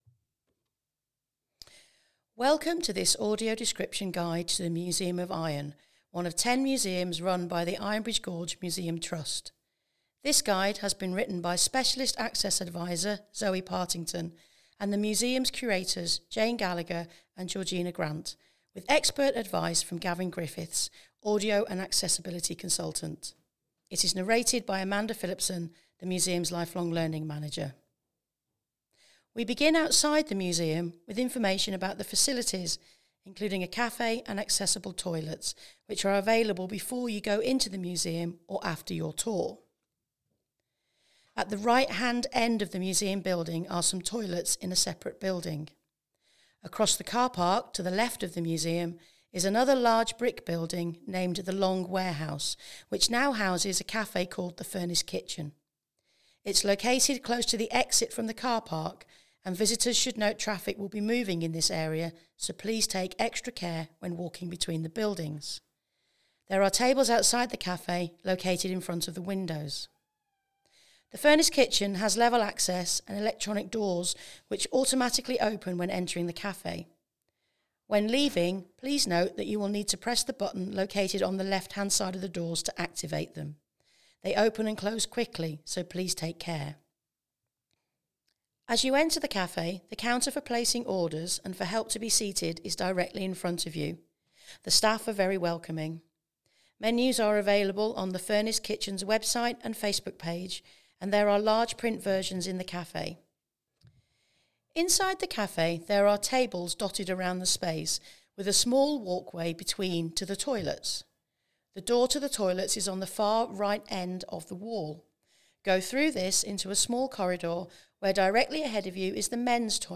Coalbrookdale Museum of Iron audio descriptive guide
coalbrookdale-museum-of-iron-audiodescriptive-tour.mp3